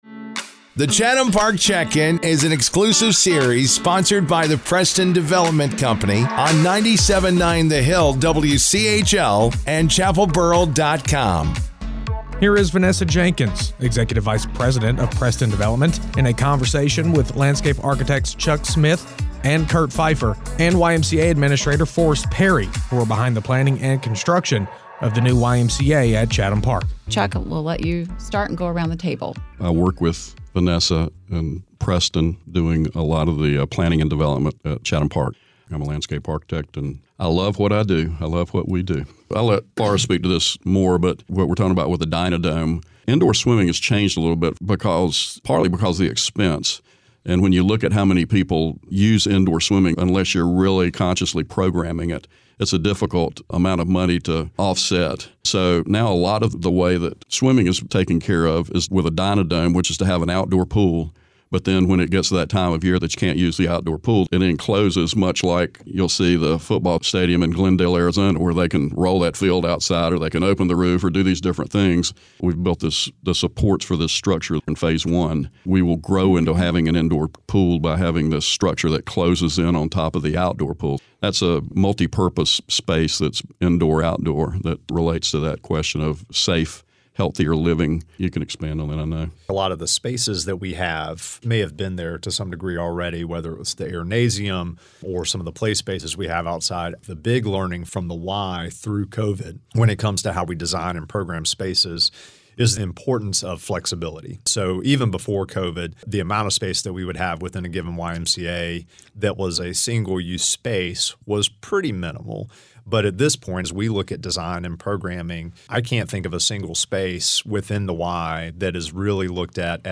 The Chatham Park Check-In continues with the next series of interviews